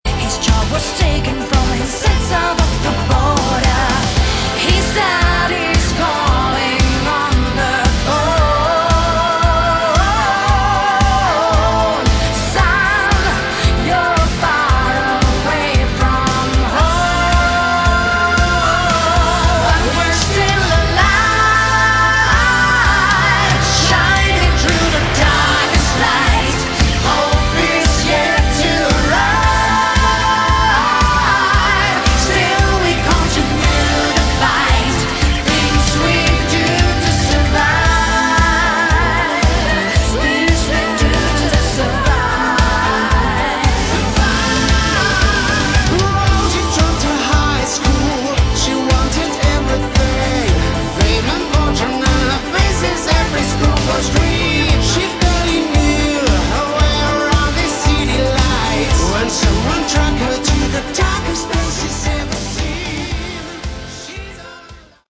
Category: Hard Rock
lead and backing vocals
guitars, keys, backing vocals
drums
bass